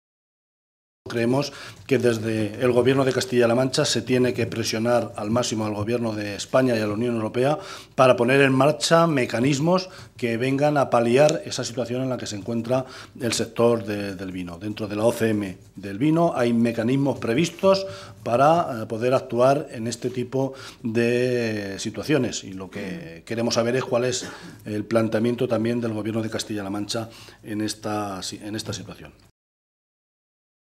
José Luis Martínez Guijarro, en rueda de prensa
Cortes de audio de la rueda de prensa